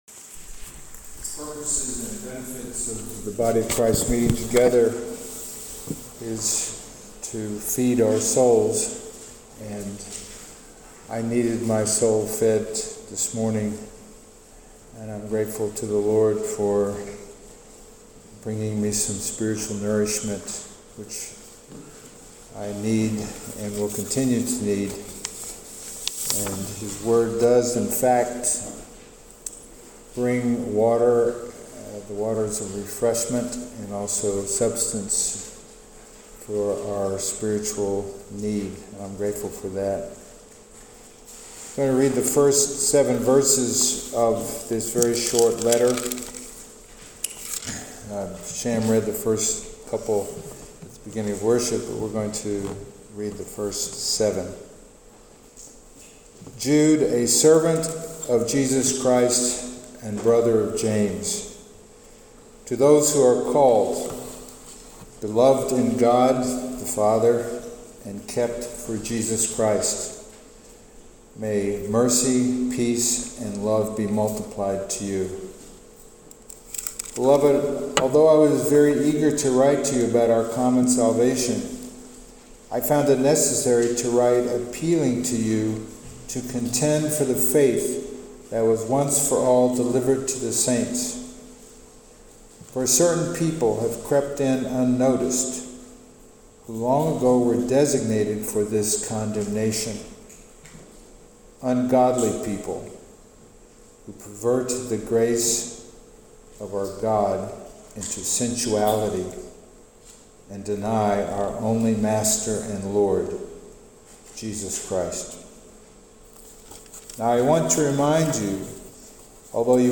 Passage: Jude 1-7 Service Type: Sunday Morning